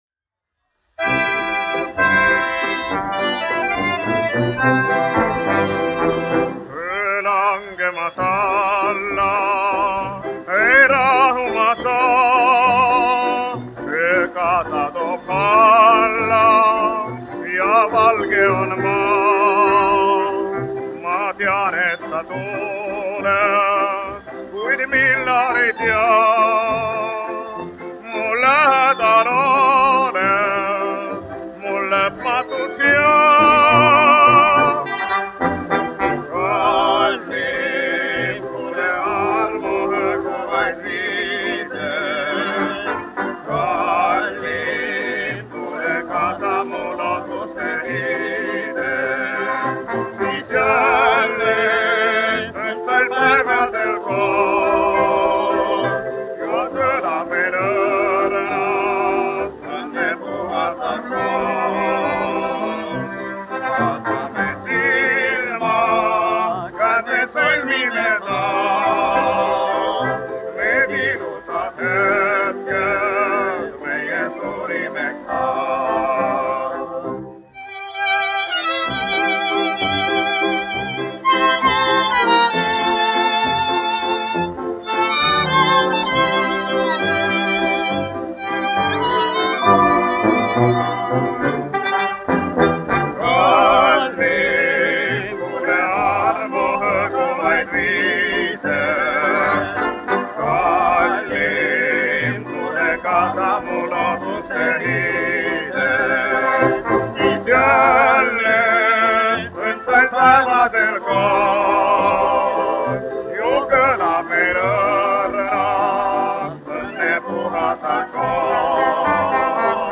1 skpl. : analogs, 78 apgr/min, mono ; 25 cm
Populārā mūzika
Valši
Skaņuplate